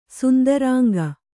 ♪ sundarānga